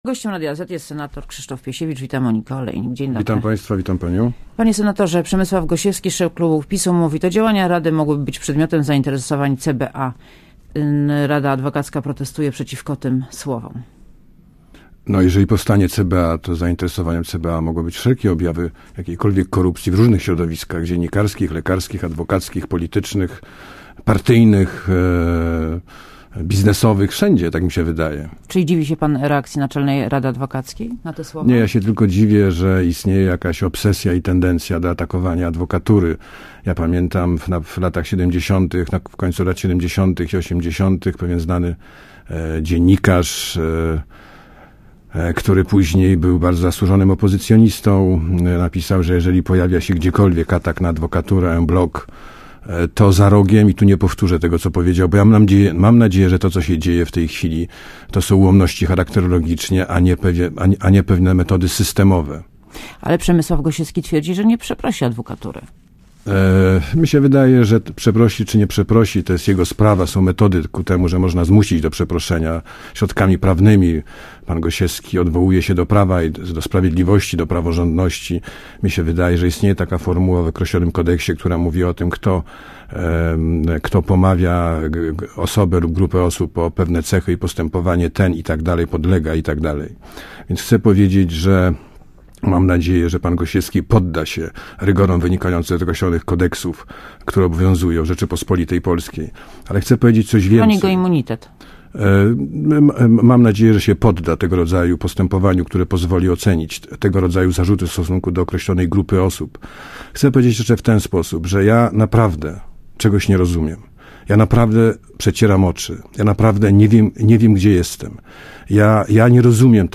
Gościem Radia ZET jest senator Krzysztof Piesiewicz. Wita Monika Olejnik, dzień dobry.